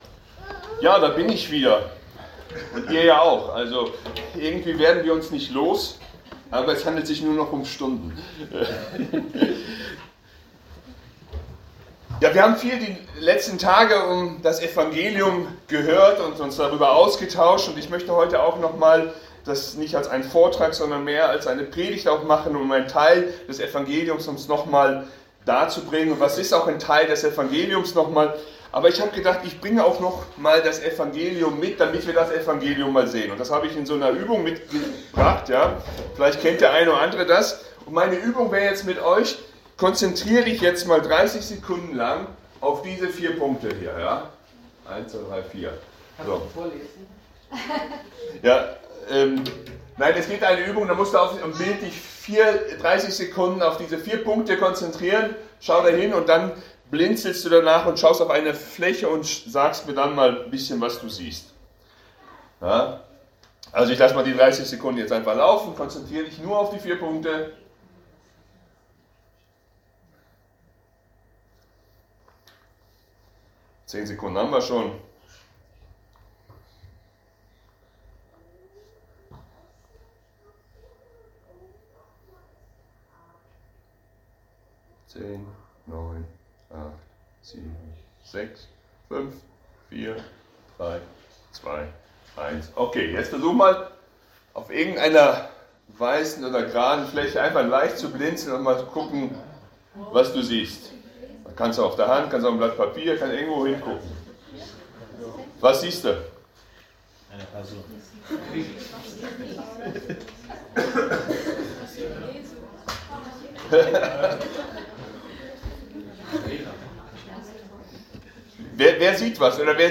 Predigten – Archiv – FEG Klagenfurt